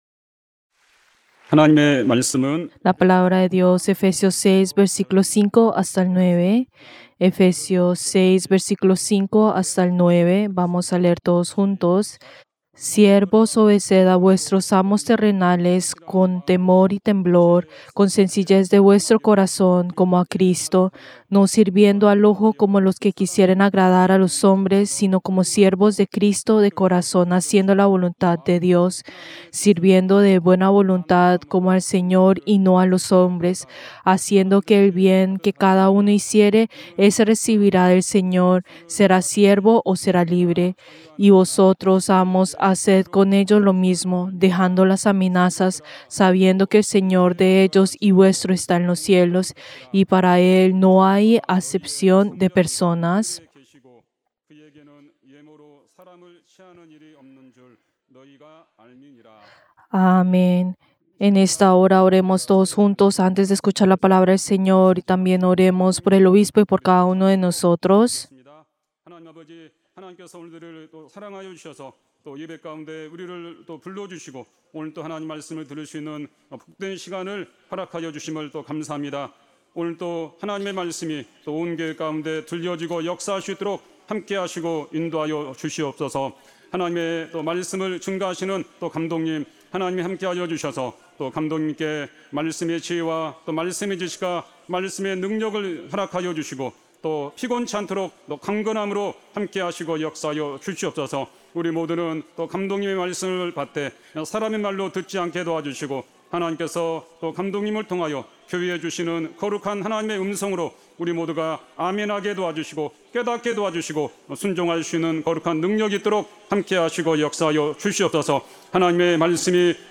Servicio del Día del Señor del 18 de mayo del 2025